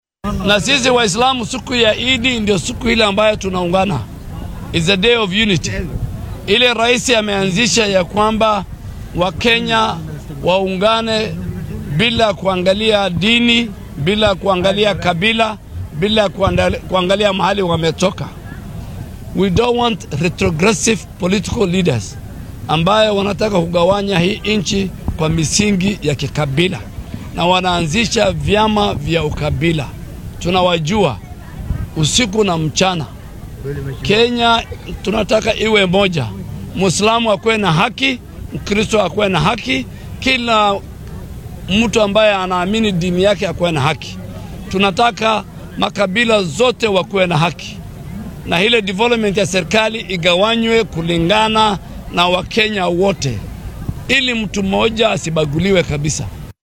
Wasiirka Wasaaradda Caafimaadka dalka Aadan Barre Ducaale ayaa carabka ku adkeeyay muhiimada ay leedahay midnimada qaranka, isagoo ku booriyay Kenyaanka inay diidaan hoggaamiyayaasha sida uu hadalka u dhigay doonaya inay hurinaan qabyaalad, nacayb iyo colaad. Ducaale oo saxaafadda la hadlay kaddib markii uu salaadda Ciidul-Adxa kula tukaday shacabka magaalada Gaarisa masaajidka Al-Abraar, ayaa sheegay in dalka uusan meel uga bannaanayn madaxdaas.